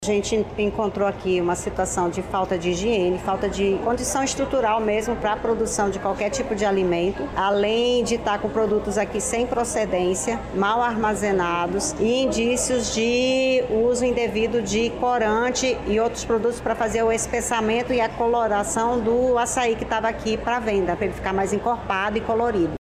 SONORA-1-INTERDICAO-COMERCIO-ACAI-.mp3